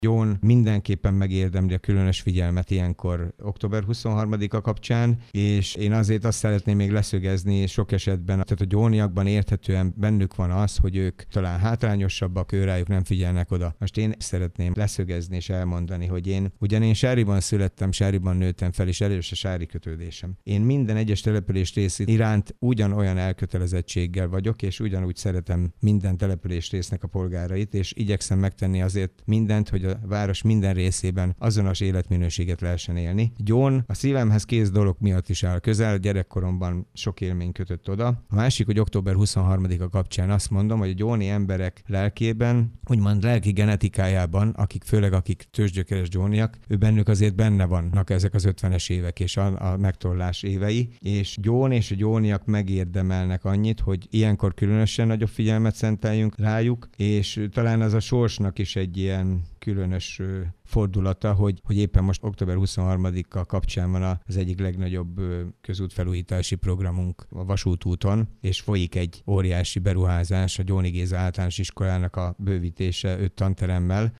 Kőszegi Zoltán dabasi polgármester az 1956 utáni gyóni események tükrében beszélt a mai gyóniakhoz.